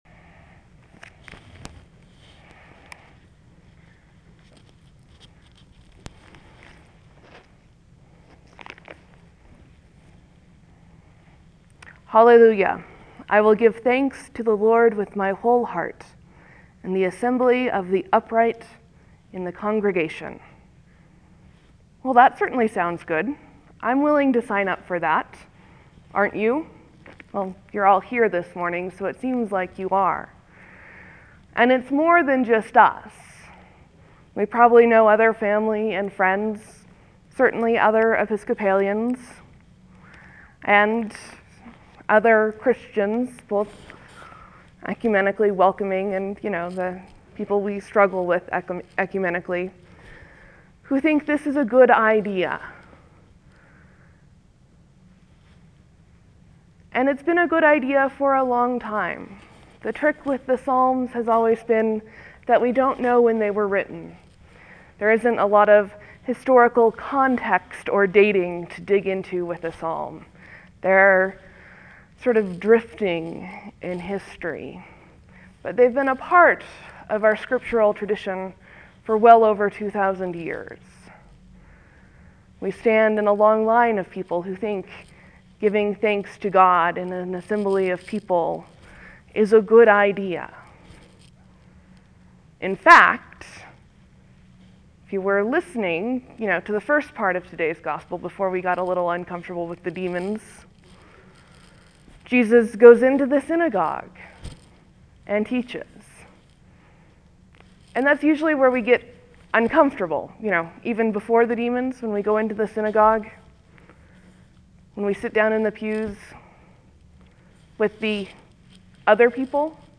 (There will be a few moments of silence before the sermon starts.